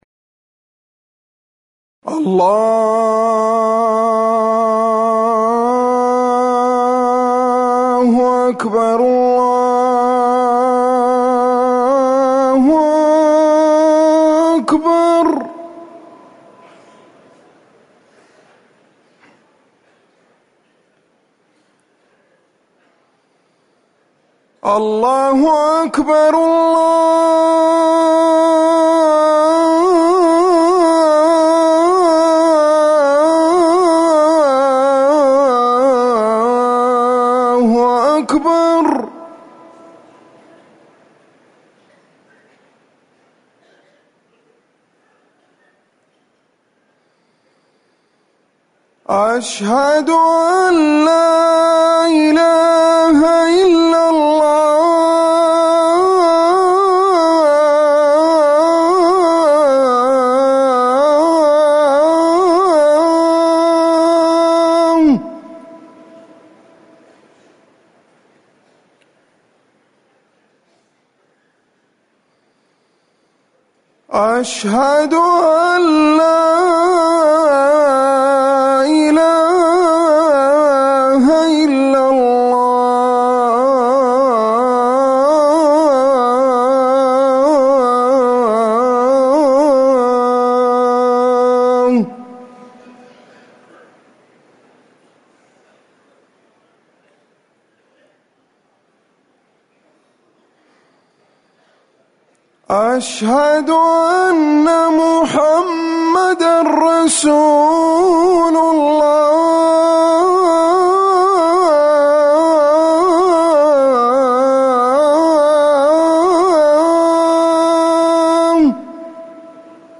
أذان الفجر الأول
المكان: المسجد النبوي